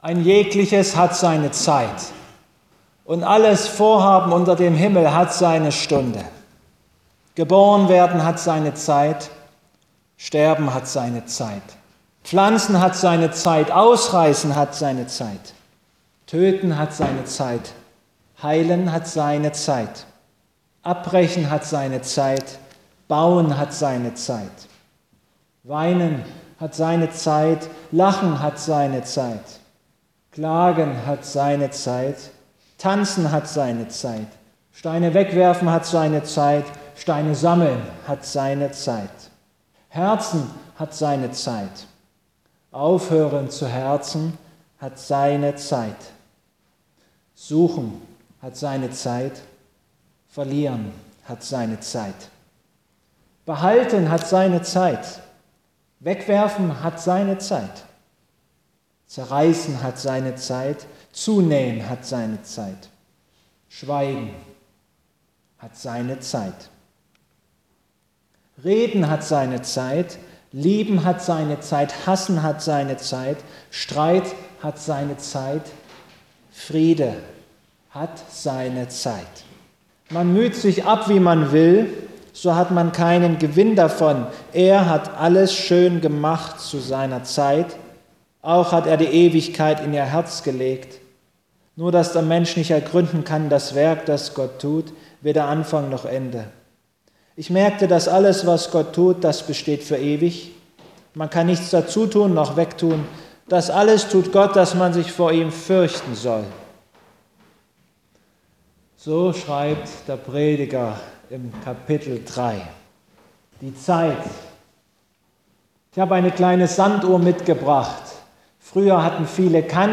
Ps 31,16 Gottesdienstart: Abendmahlsgottesdienst "Und guten Rutsch!"